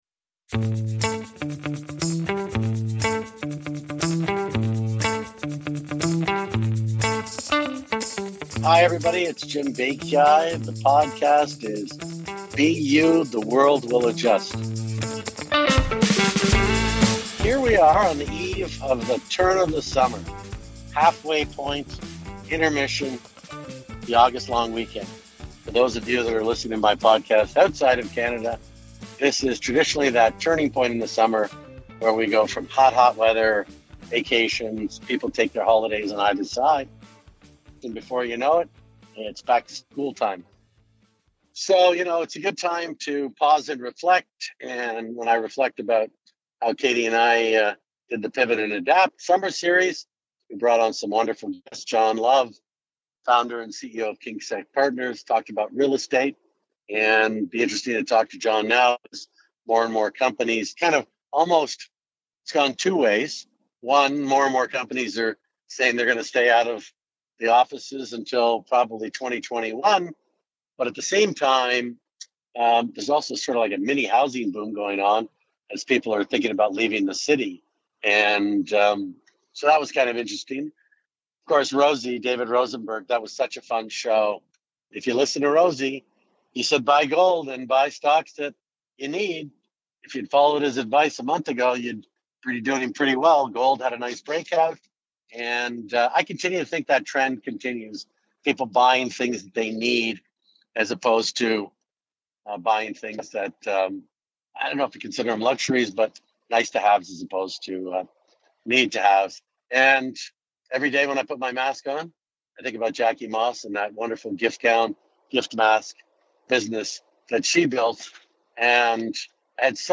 In this episode, I take some time during a car ride to reflect on what I've learned from these conversations and how I'm using that information to navigate the world today.